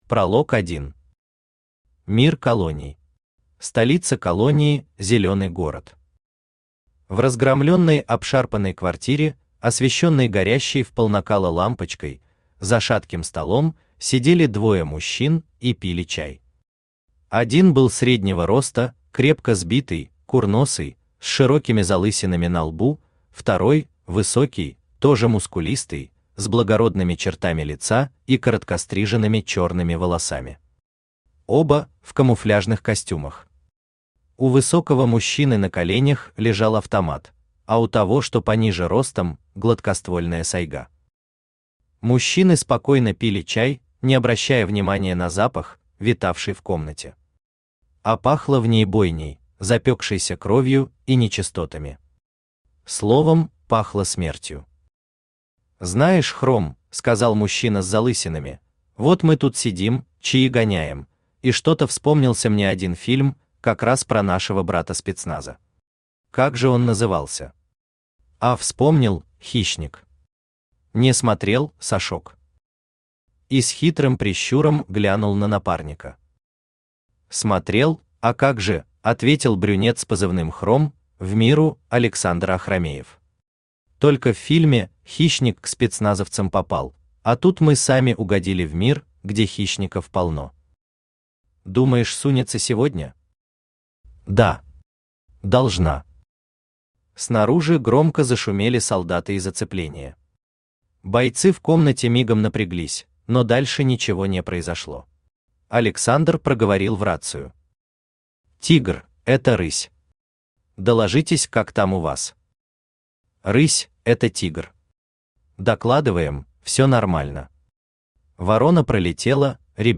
Аудиокнига Пограничник | Библиотека аудиокниг
Aудиокнига Пограничник Автор Павел Мамонтов Читает аудиокнигу Авточтец ЛитРес.